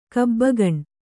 ♪ kabbagaṇ